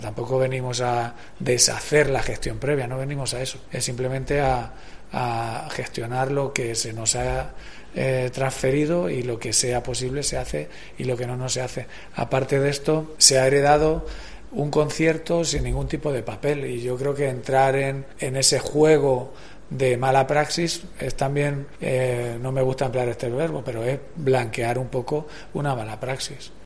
Juan López, concejal de cultura de Puertollano sobre el "concierto de Manuel Carrasco"